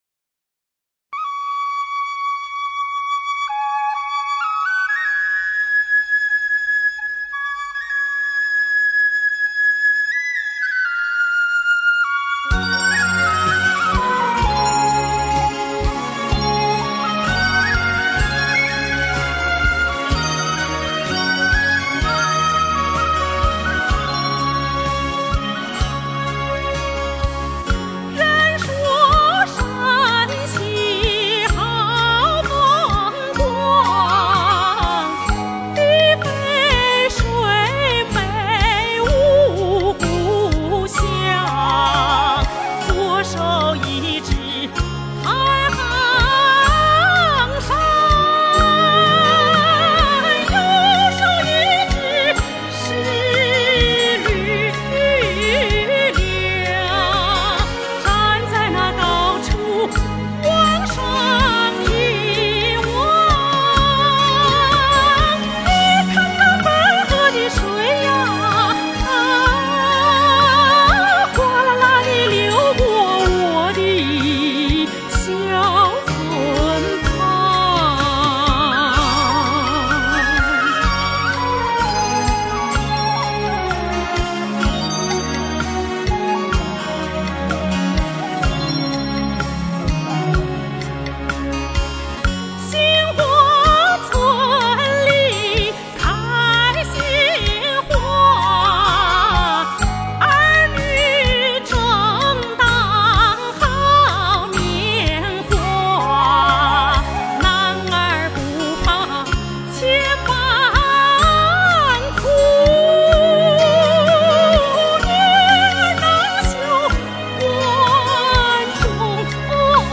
她的演唱自然流畅，音色富于磁性和女性音色美